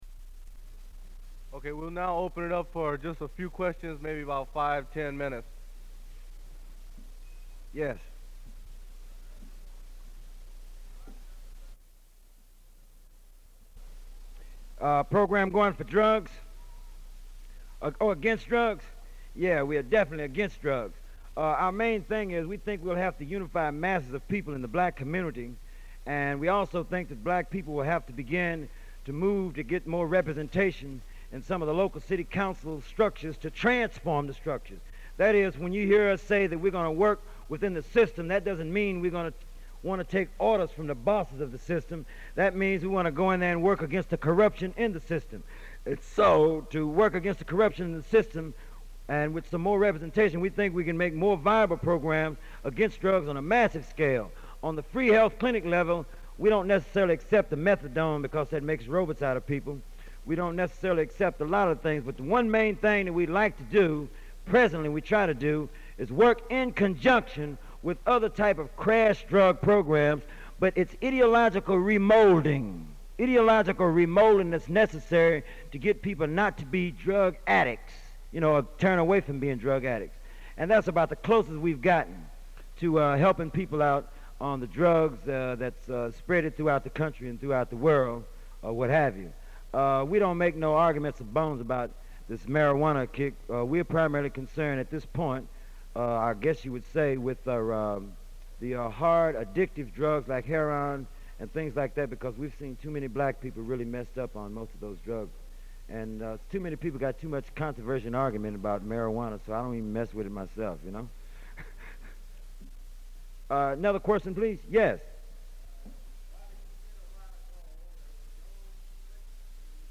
Recording of speech made by Bobby Seale (co-founder of the Black Panther Party and activist for the Civil Rights Movement) on Cal Poly campus. Side B is a Q and A session after Seale's speech.
Form of original Open reel audiotape